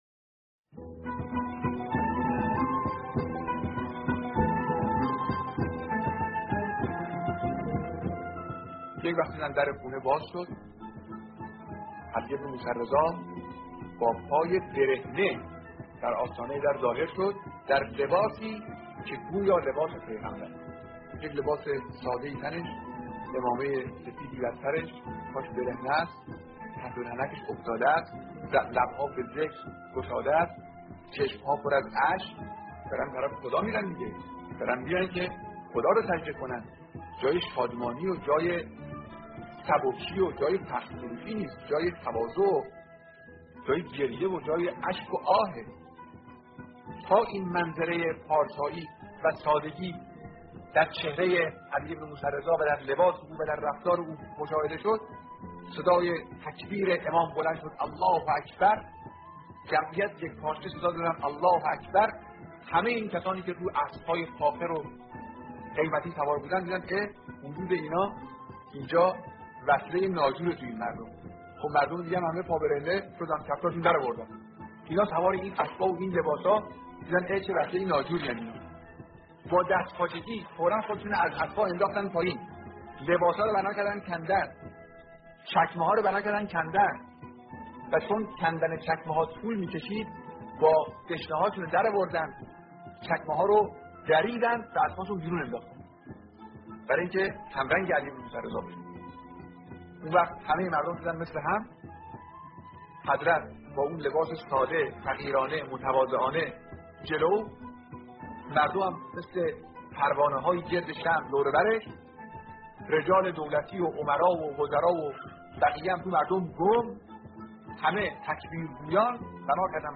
سخنرانی قدیمی رهبر انقلاب در مورد امام رضا علیه‌السلام